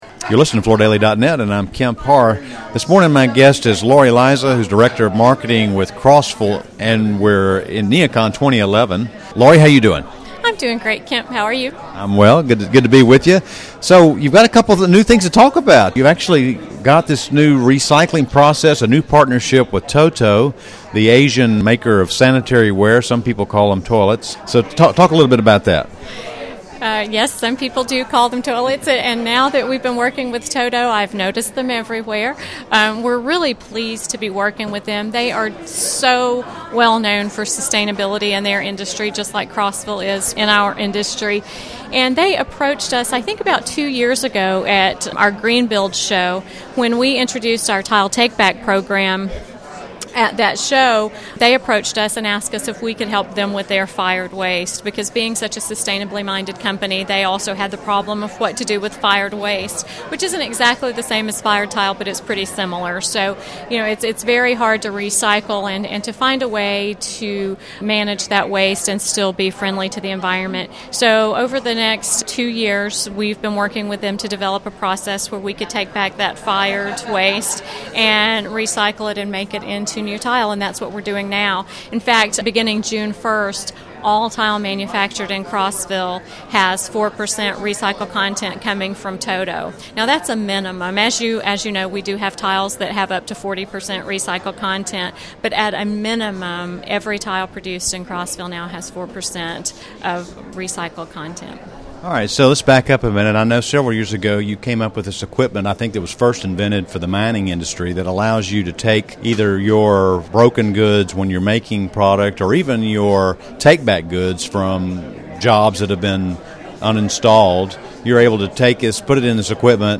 Listen to the interview to hear more Crossville's news at NeoCon 2011.